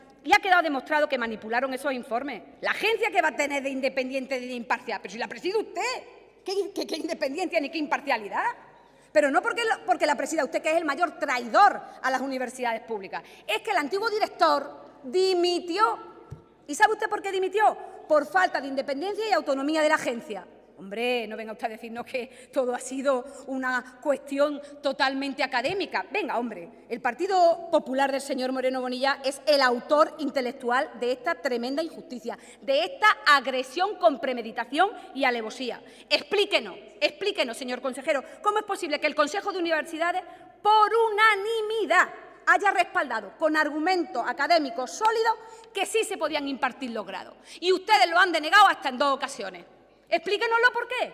En el Pleno del Parlamento
Angeles-Ferriz-UJA.mp3